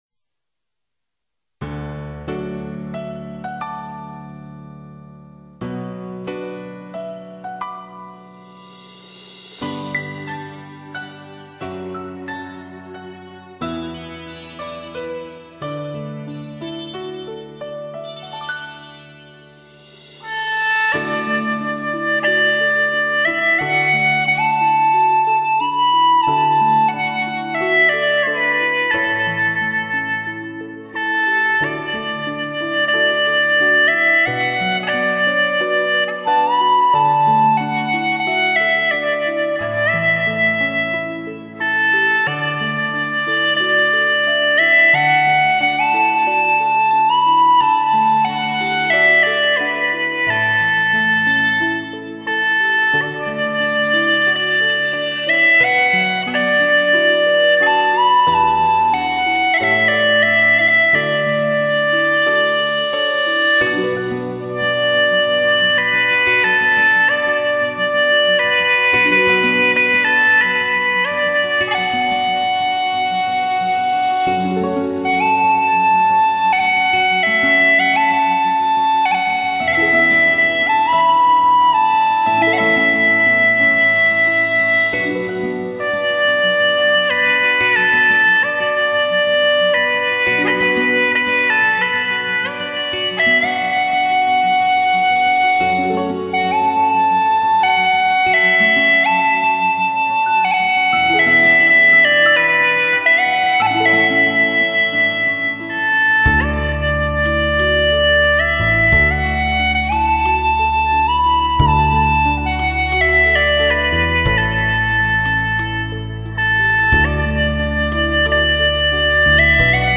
箫、巴乌演奏